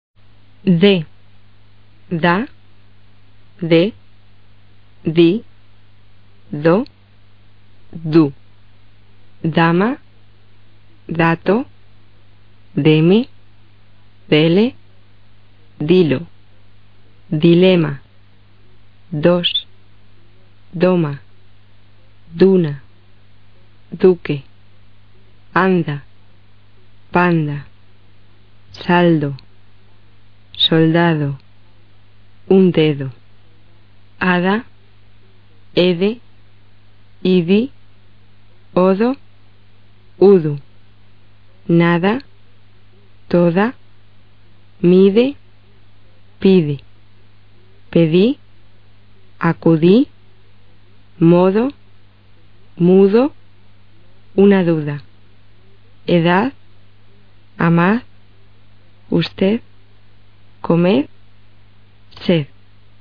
D发音：
与【t】的区别是，【d】是浊辅音，声带振动。